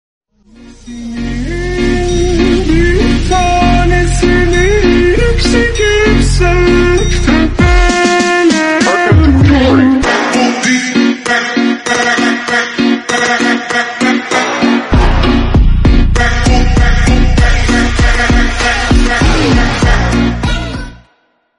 S1000RR And M1000RR Full Carbon Sound Effects Free Download
S1000RR and M1000RR full carbon